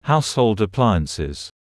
18.household appliances /ˈhaʊshəʊld əˈplaɪənsɪz/ (n.phr): đồ dùng gia đình